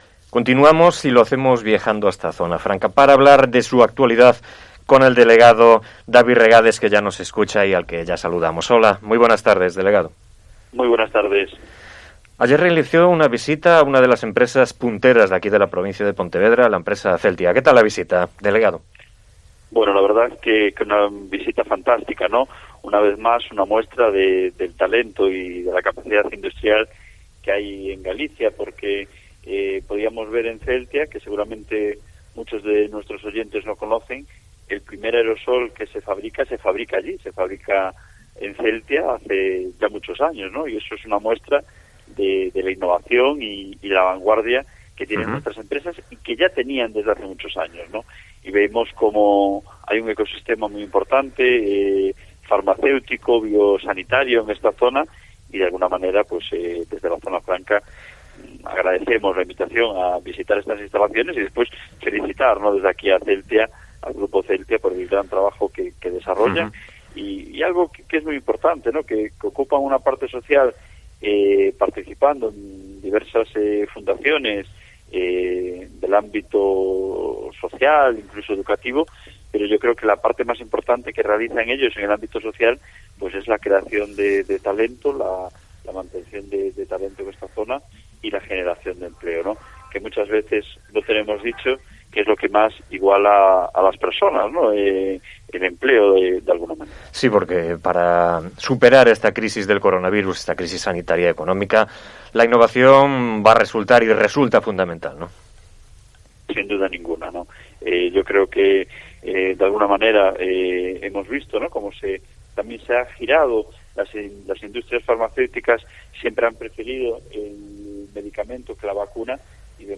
Entrevista con David Regades, delegado de Zona Franca de Vigo